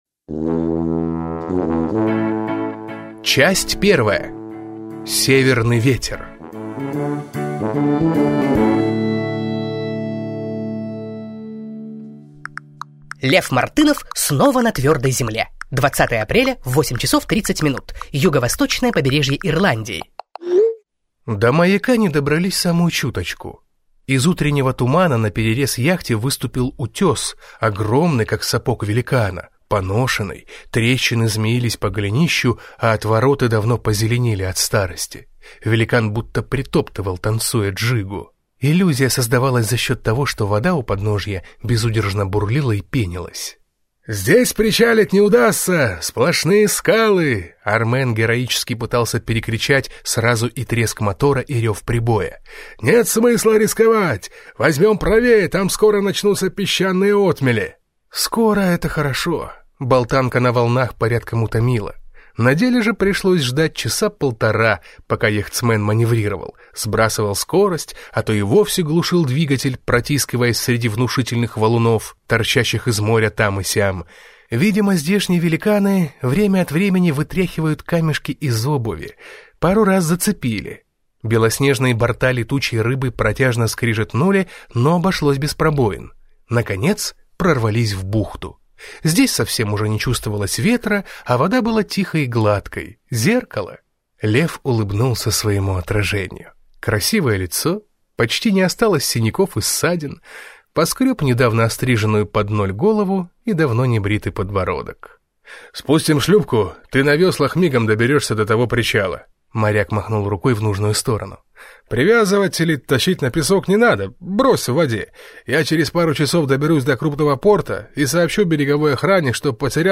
Аудиокнига Перелётный жених. Книга вторая | Библиотека аудиокниг